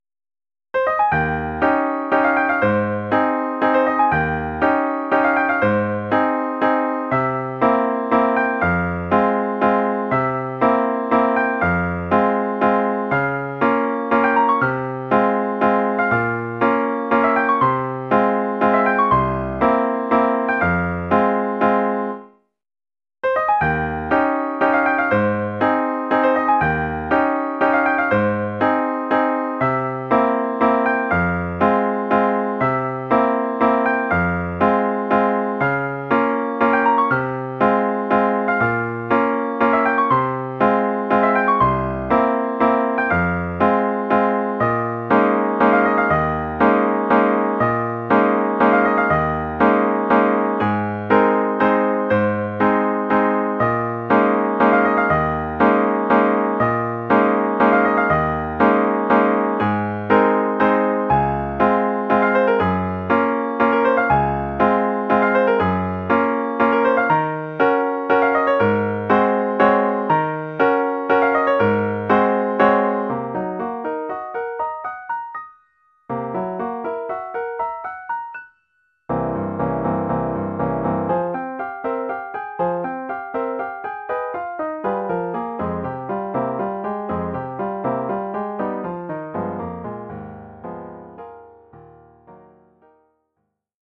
1 titre, piano solo : partie de piano
Oeuvre pour piano solo.